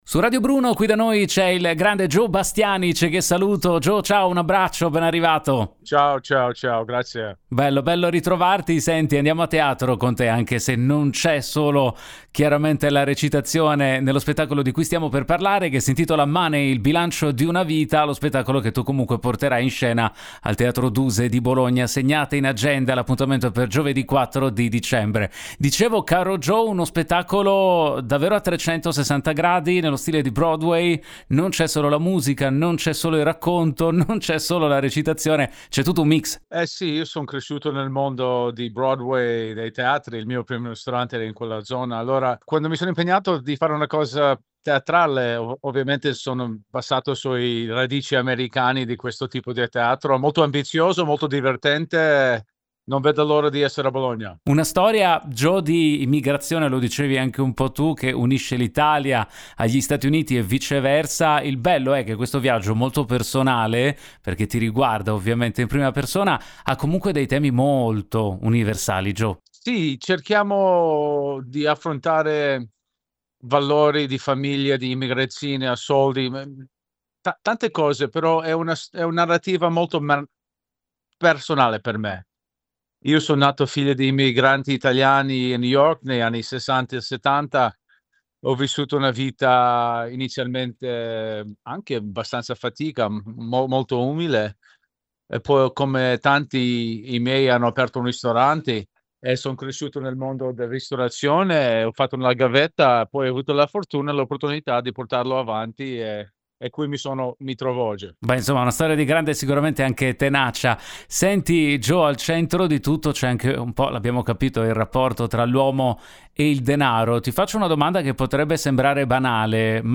Home Magazine Interviste Joe Bastianich presenta “Money – Il bilancio di una vita”